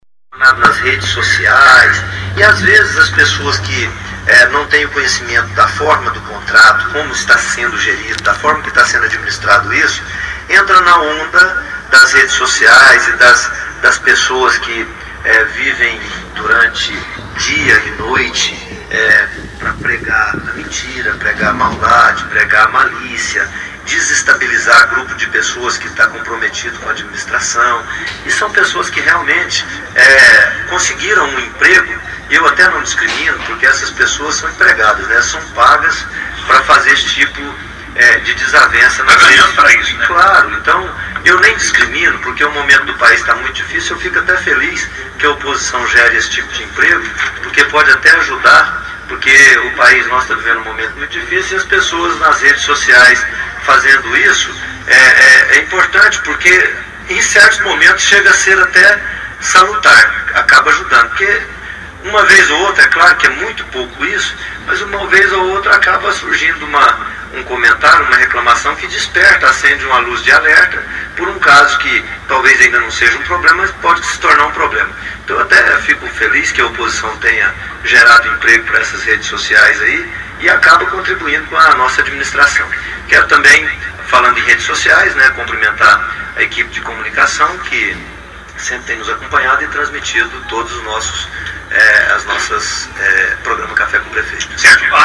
No Programa Café com o Prefeito, transmitido pela Rádio Cidade de Jaraguá – Zilomar Oliveira (PSDB) discorreu sobre vários temas da administração, incluído as obras do Governo de Goiás no fim da gestão de Marconi Perillo, além de dizer que a oposição gera emprego nas redes sociais.